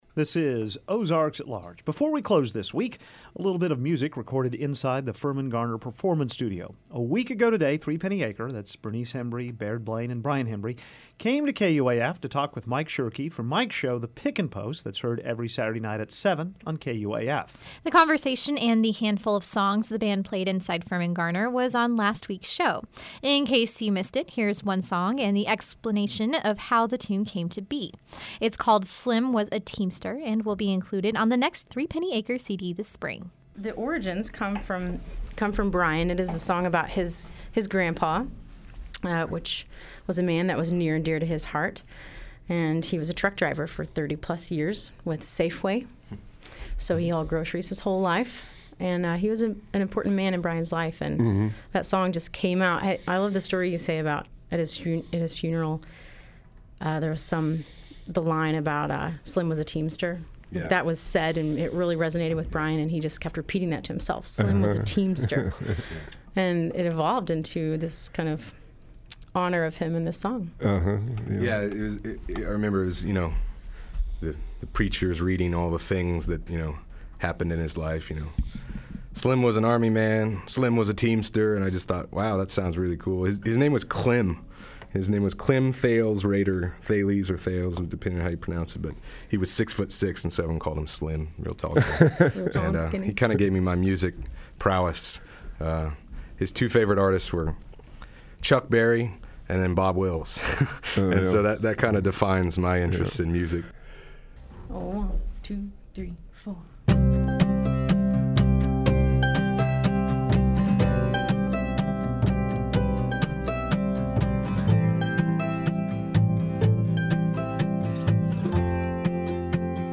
band